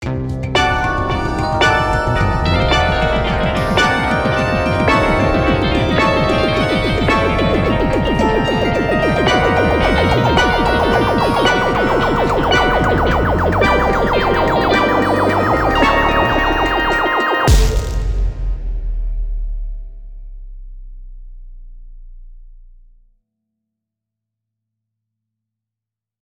Noem het maar op. Het enorme kakafonie van geluid liet het ijs barsten waardoor de parel in de hand van de visser viel. Op dat moment viel het geluid ook stil.
Breek.mp3